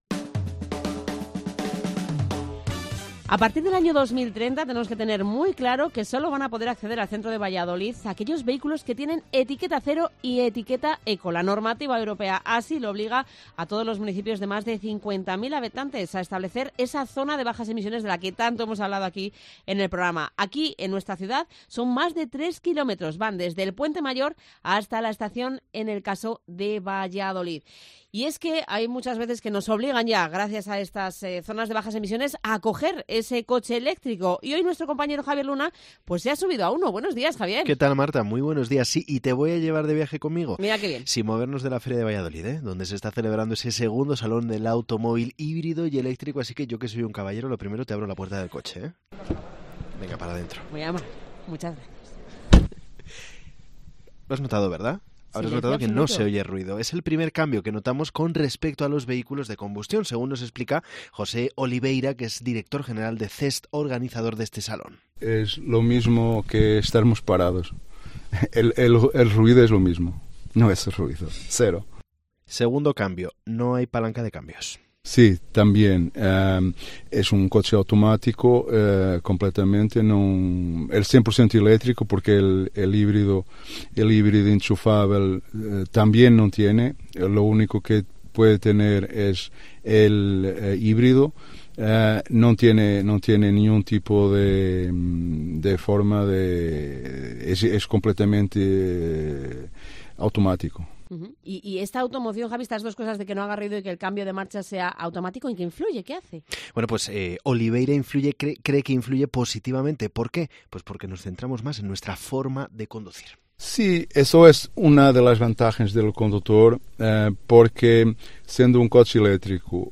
II Salón del Automóvil Híbrido y Eléctrico en la Feria de Valladolid: "Tiene ventajas. El consumo es menor"